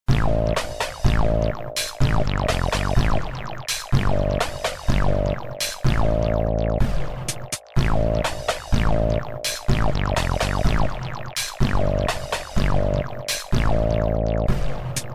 Adding a Bass Line
The Freeverb is from the Reverb collection of effects and generates a reverberation (a sort of echoey resonance) - notice that I have also panned the freeverb to the left of the stereo field.
The combination provides an interesting stereo picture of bassline effects.